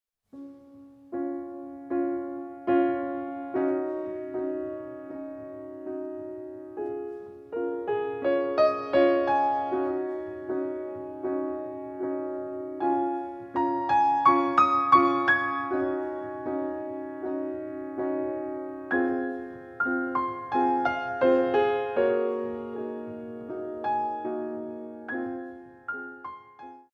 Adage in the Centre